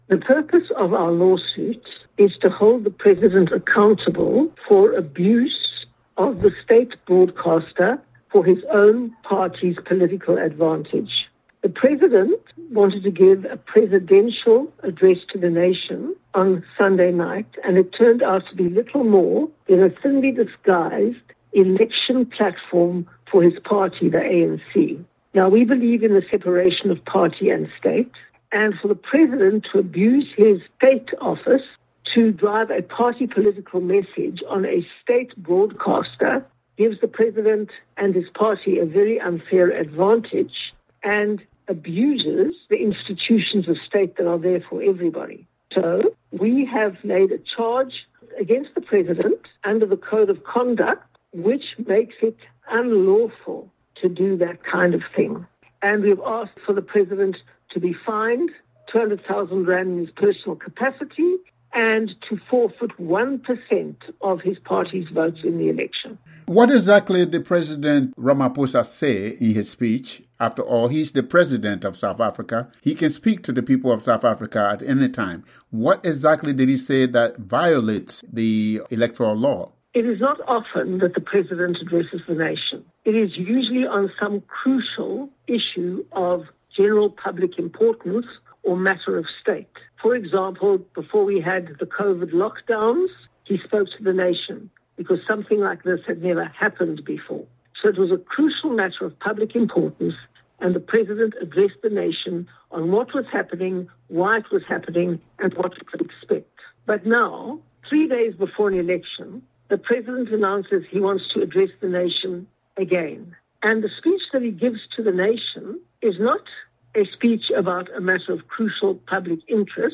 Helen Zille, chairperson of the DA’s federal council